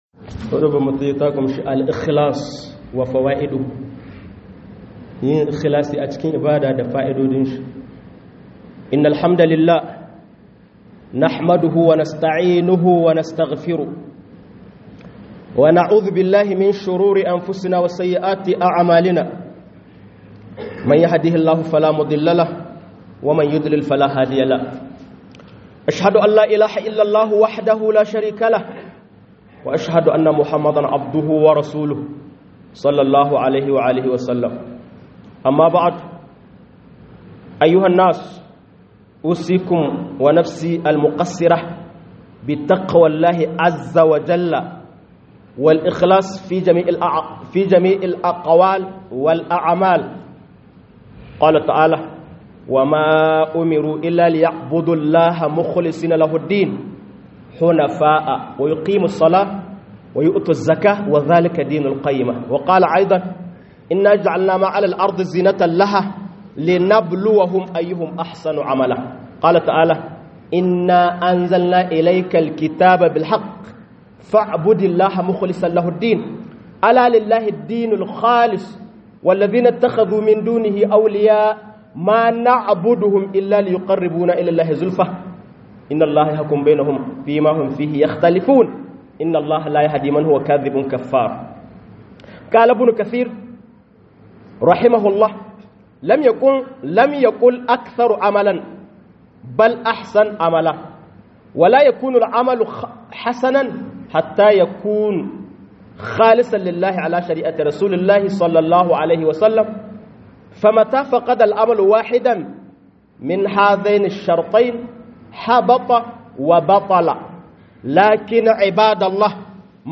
KHUƊUBAR JUMA'A